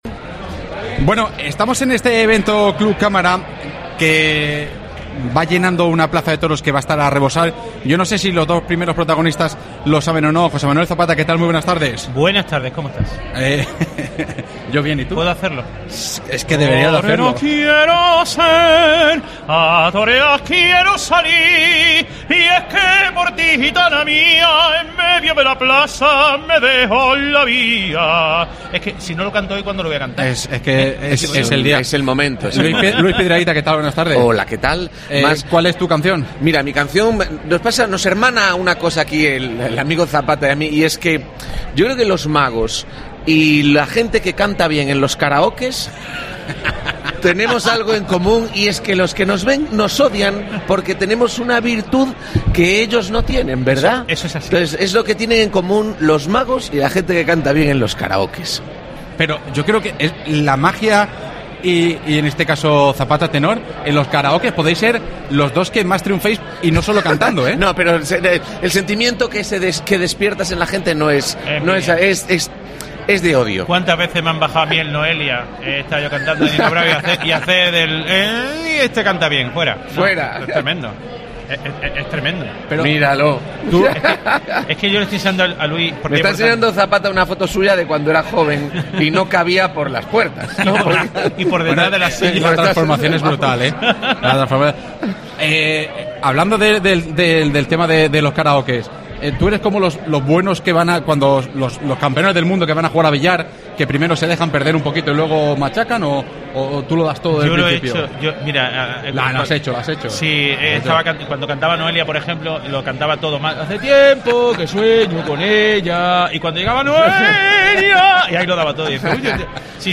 Programa Especial desde el Encuentro Club Cámara 2023